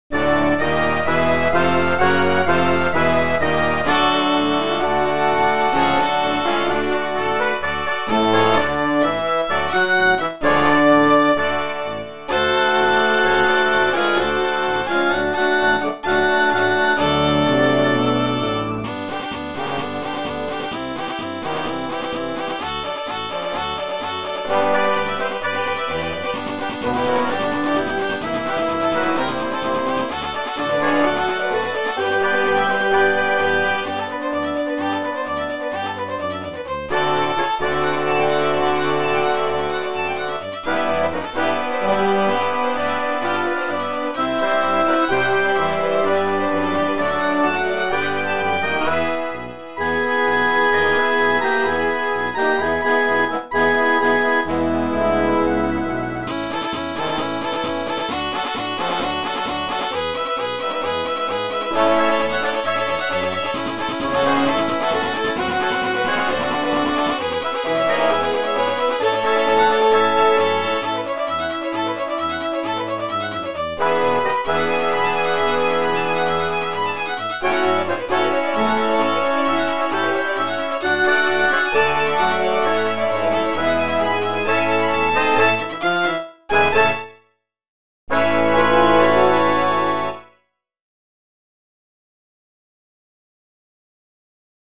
2025 Laker Marching Band Halftime Shows
Orange Blossom Special / Guest Fiddle Players
2025_orange_blossom_with_fiddle-a.mp3